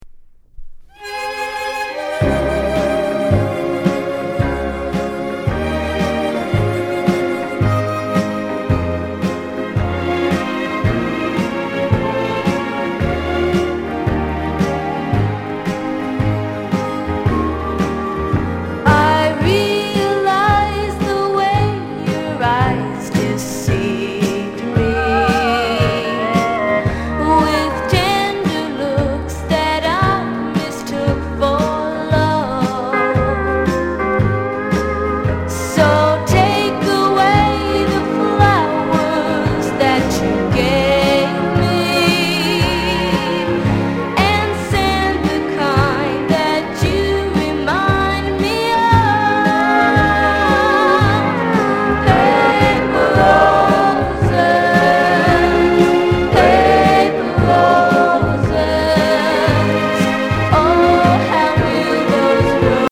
SOUND CONDITION EX-